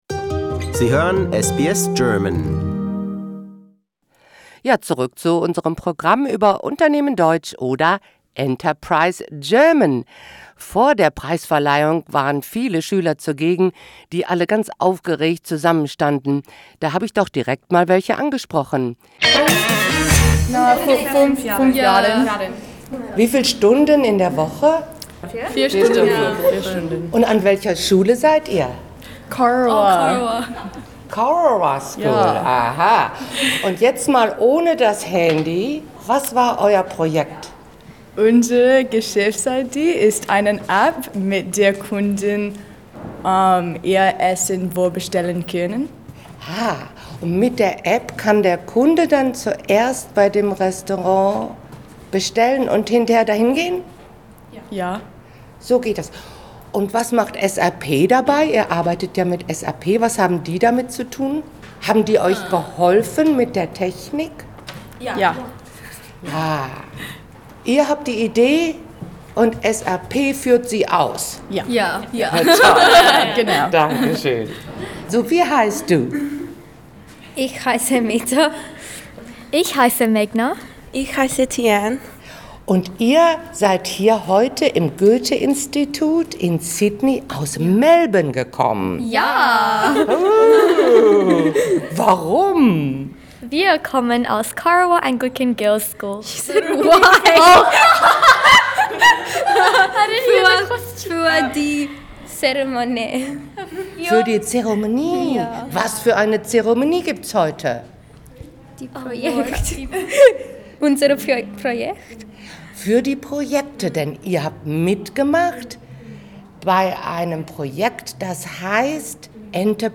The award ceremony for the Australia based groups took place on the 8th of November and SBS German was a guest. We hear from students, teachers, managers of the companies Aldi, King of Cakes, and SAP, and the deputy German Consul General in Sydney Klaus Steitz.